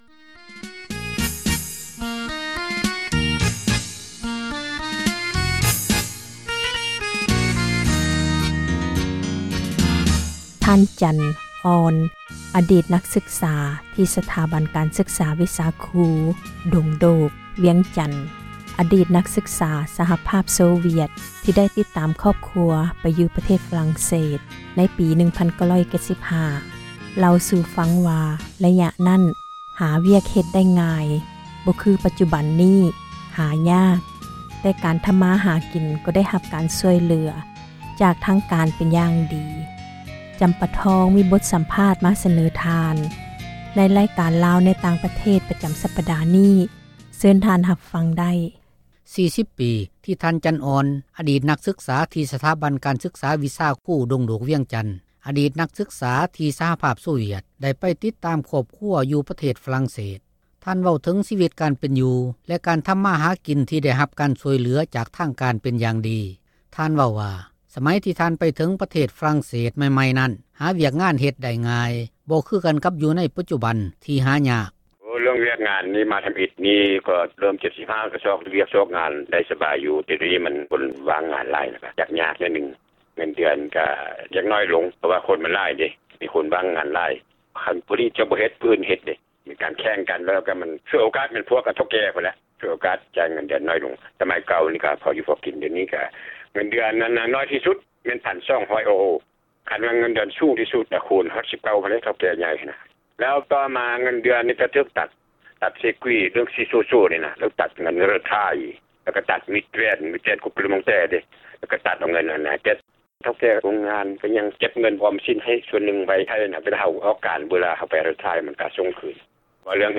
F-Laos ສໍາພາດ